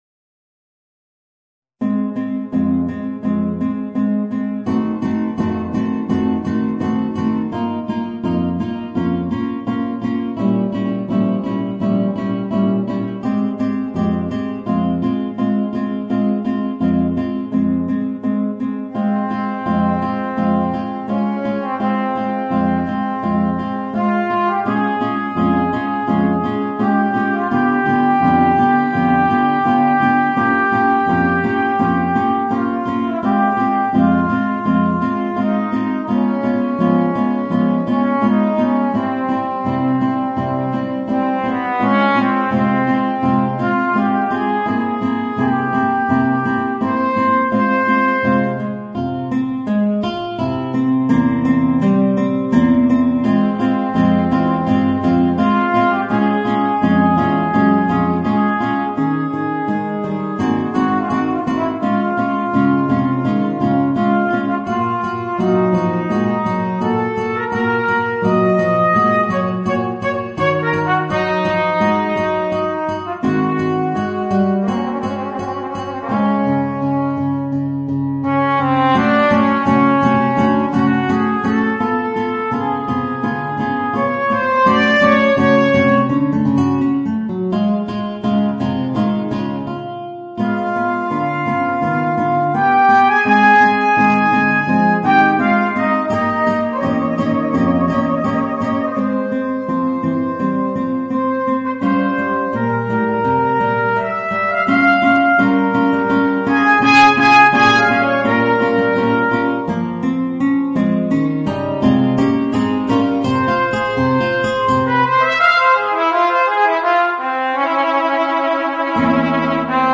Voicing: Guitar and Trumpet